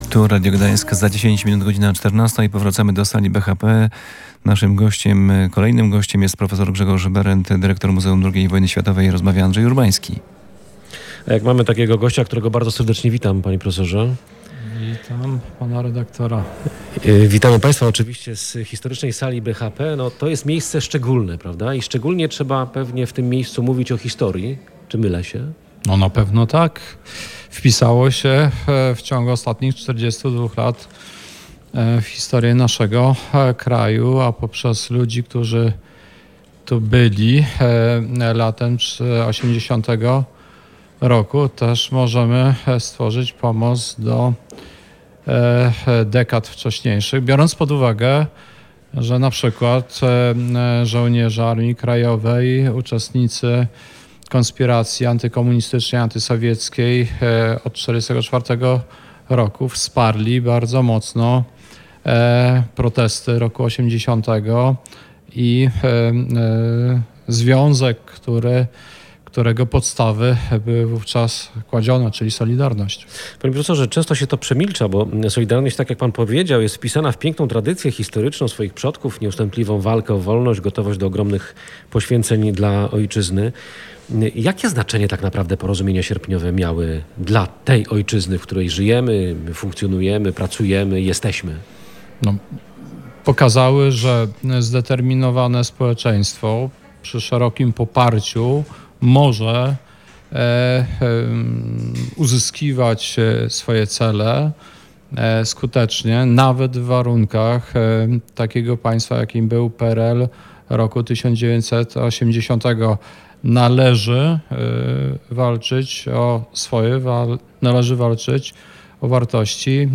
Uroczyste obchody rocznicy podpisania Porozumień Sierpniowych. W środę Radio Gdańsk nadawało z historycznej sali BHP.